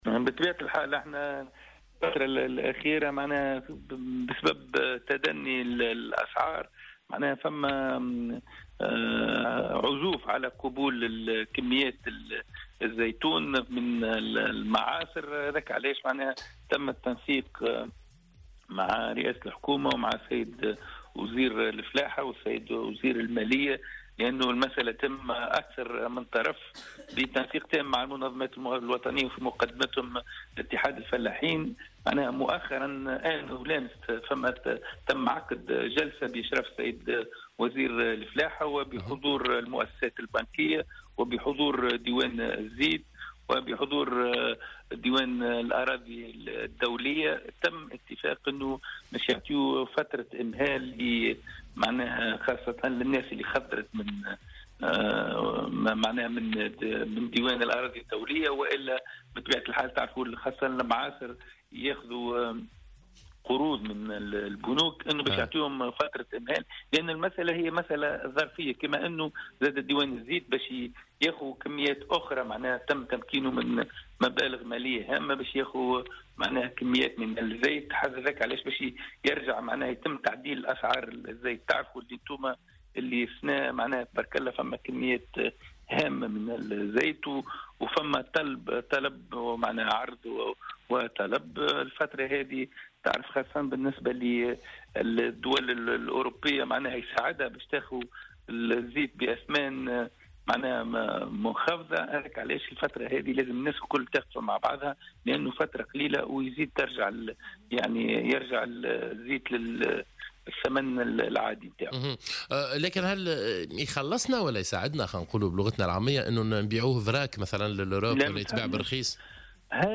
أكد والي صفاقس، عادل الخبثاني في تصريح اليوم لـ"الجوهرة أف أم" أنه تم التوصل إلى حلّ وذلك على إثر احتجاج عدد من الفلاحين بسبب ارتفاع فائض زيت الزيتون بمعاصر الجهة وعزوف المصدرين عن قبول الكميات.